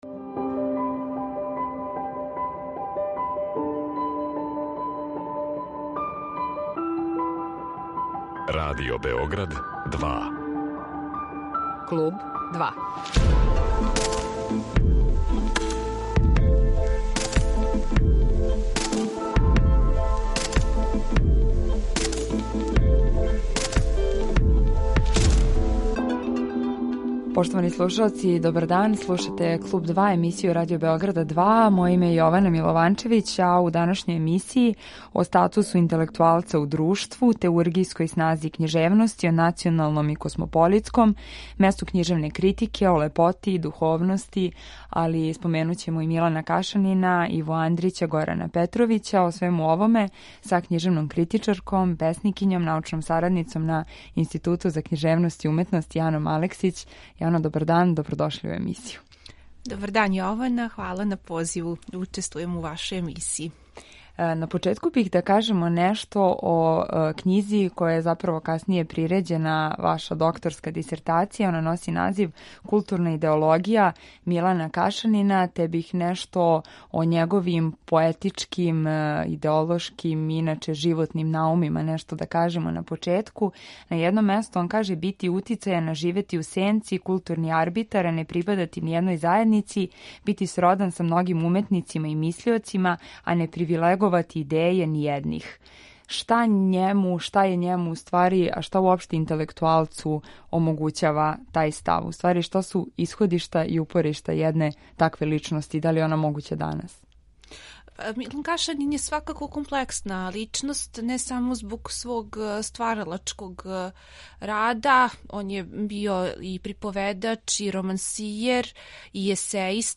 У емисији ћемо говорити о њеним до сада објављеним научним монографијама, песничком раду, статусу интелектуалца у друштву, теургијској снази књижевности, о националном и космополитском, месту књижевне критике, о лепоти и духовности, али и o Милану Кашанину, Иви Андрићу, Горану Петровићу. Разговор води